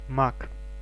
Ääntäminen
IPA : /ˈwɪz.əd/